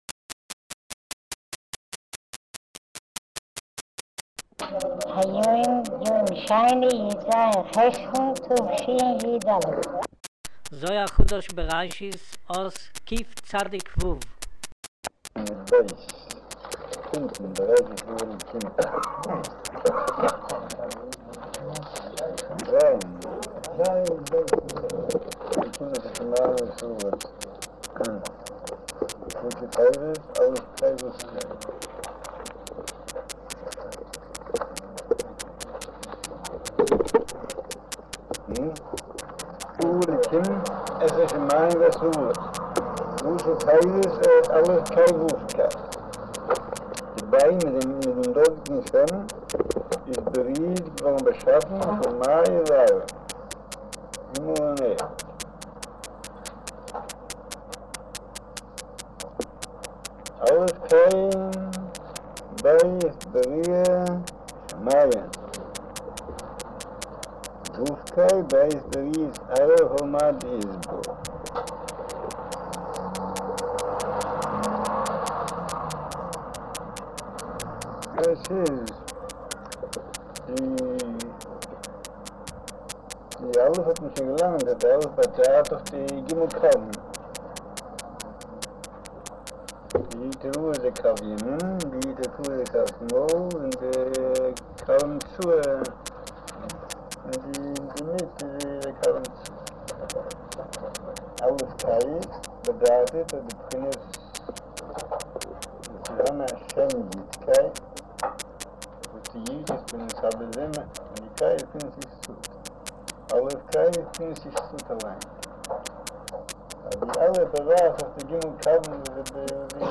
אודיו - שיעור מבעל הסולם זהר חדש, בראשית, אות קצו'